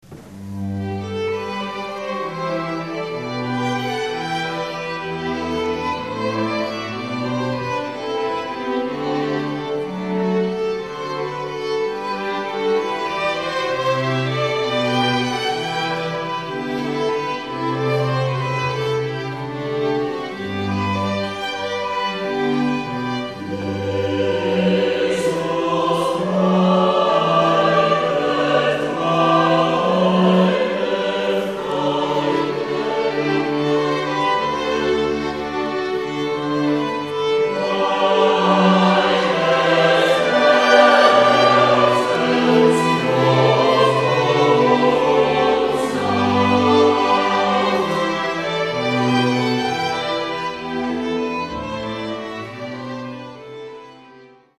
Festliche Streicherklänge zur Zeremonie
A Streichquartett (unsere Grundbesetzung: 2 Violinen, 1 Viola, 1 Violoncello)
(Besetzung A: Streichquartett)